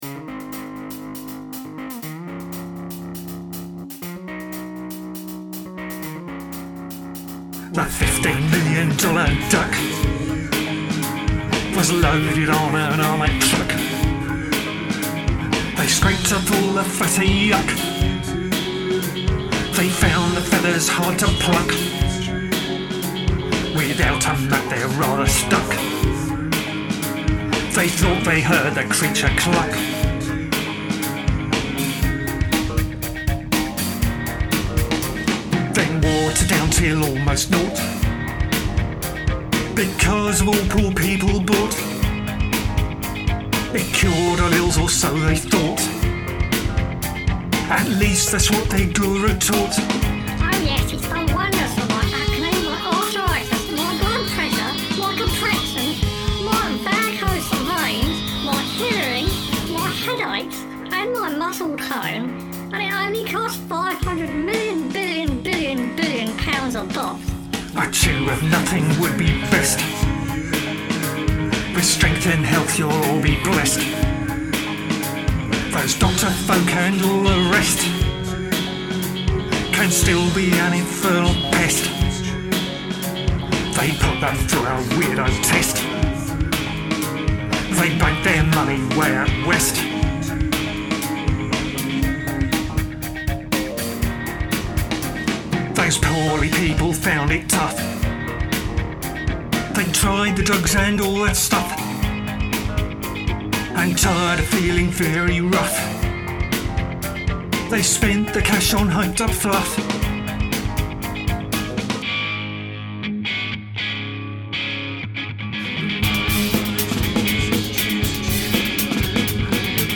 I didn't of course know, so it turned into an almost psychadelic stream of consciousness.